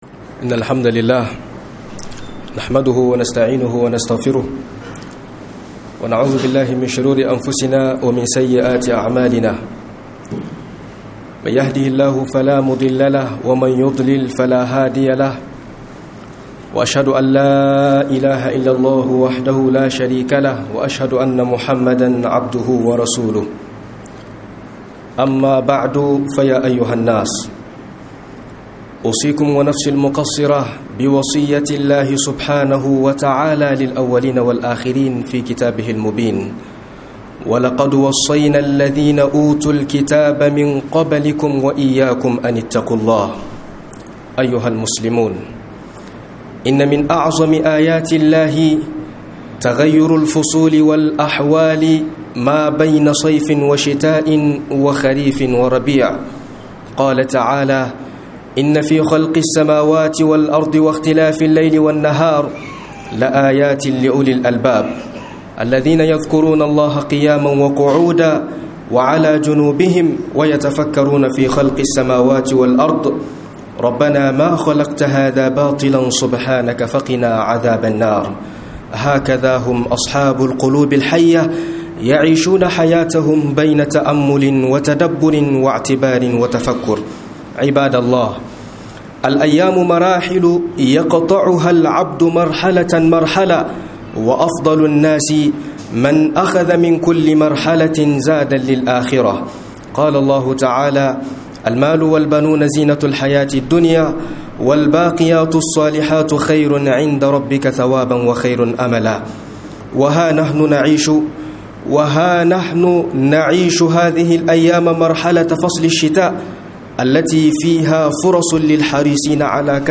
AZUMI DA KIYAMU LAYLI A LOKACIN SANYI - MUHADARA